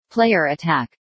Player_Attack.ogg